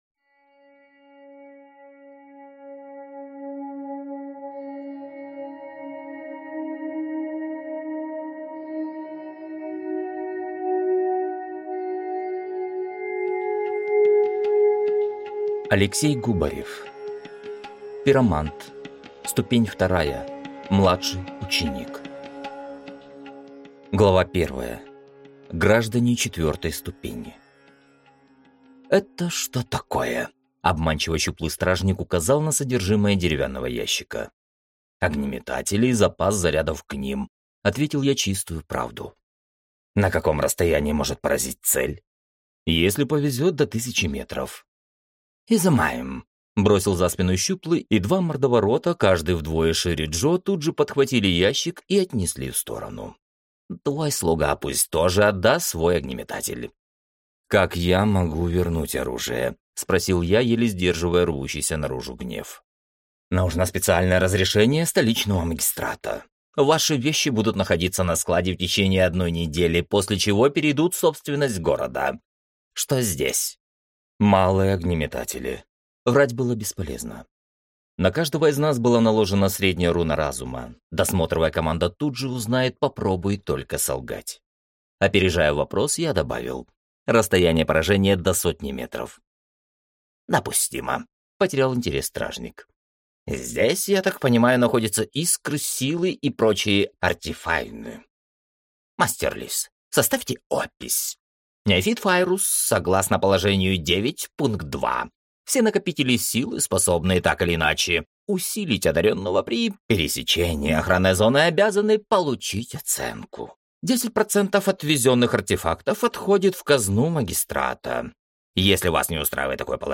Аудио 2023-04-25 19:46 ☘ Жанры книги: Аудиокниги про попаданцев Алексей Губарев.